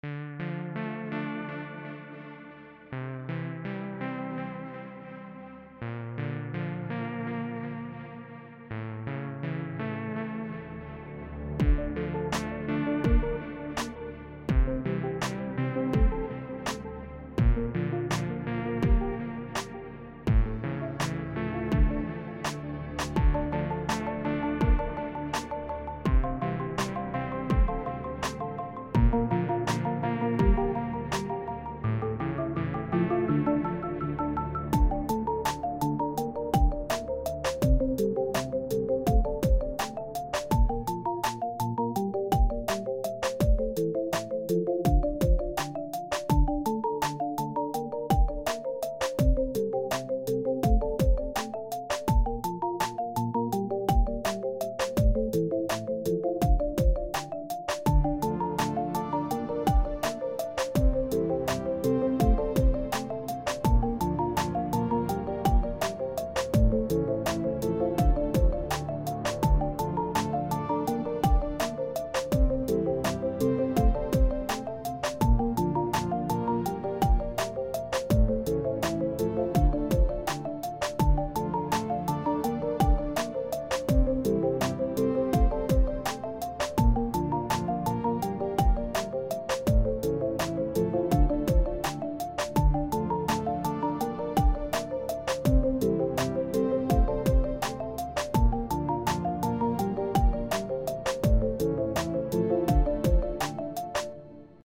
It's also very repetitive and unfinished, but I will probably do something with it later.
It's sort of mellow but I like it a lot.
I was going for mellow.